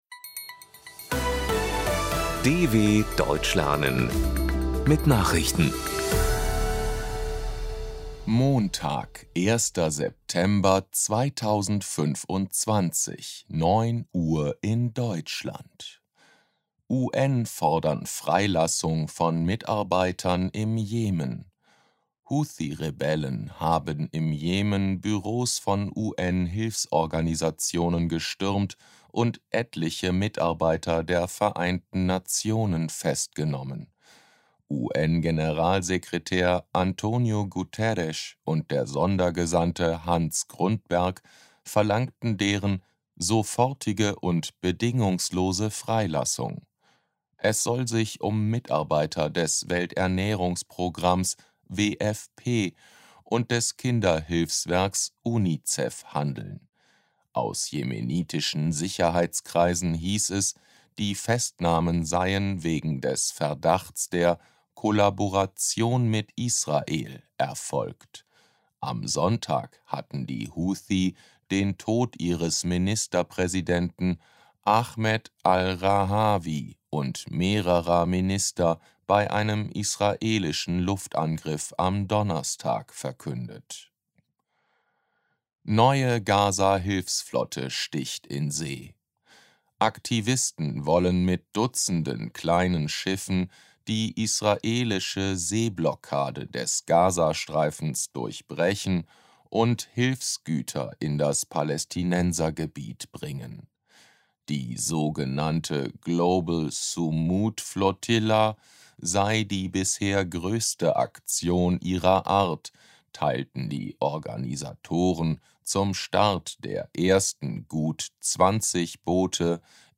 01.09.2025 – Langsam Gesprochene Nachrichten
B2 | Deutsch für Fortgeschrittene: Verbessert euer Deutsch mit aktuellen Tagesnachrichten der Deutschen Welle – für Deutschlerner besonders langsam und deutlich gesprochen.
– als Text und als verständlich gesprochene Audio-Datei.